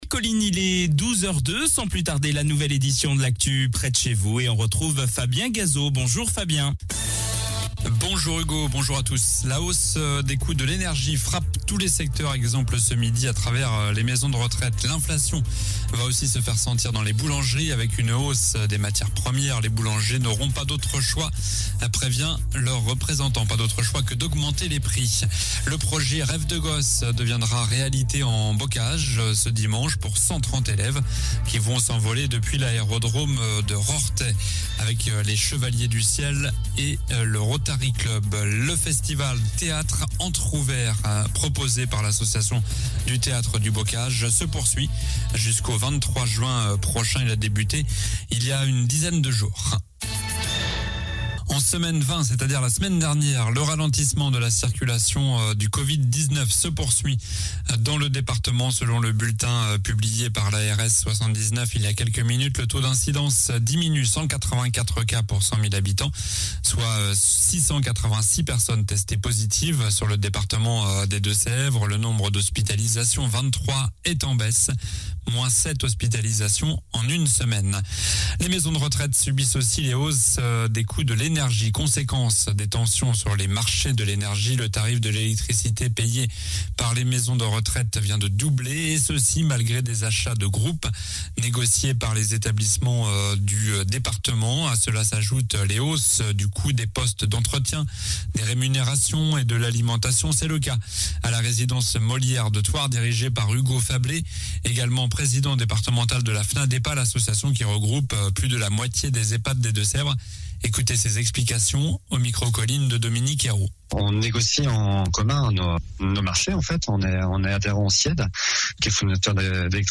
Journal du mercredi 25 mai (midi)